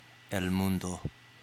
Ääntäminen
Ääntäminen CA : IPA : [wɝəɫd̥]